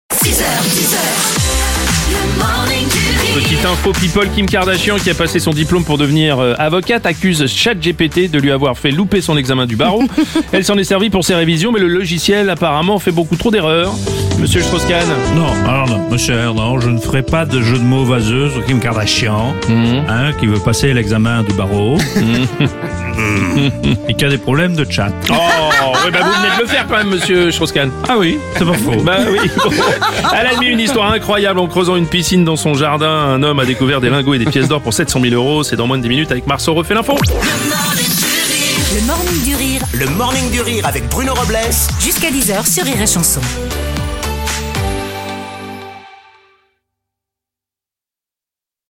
L’imitateur